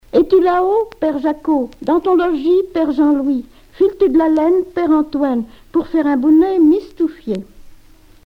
enfantine : comptine
Catégorie Pièce musicale éditée